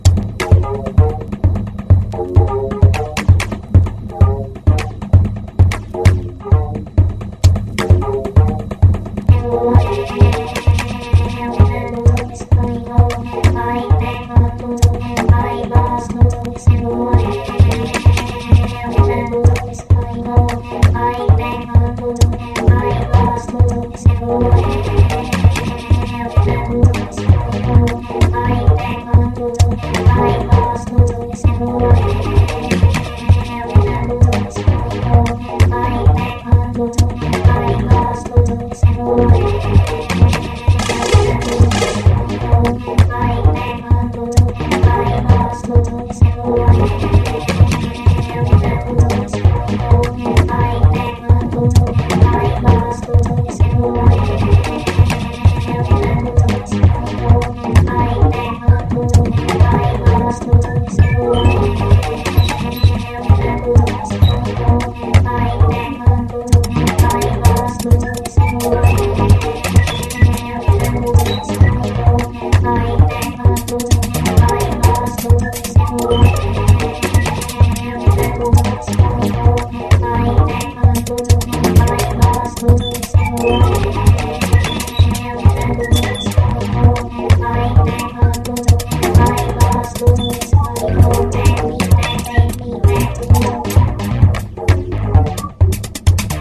シンプルな素材でひねてるレトロフューチャーなジャッキン/エレクトロトラック。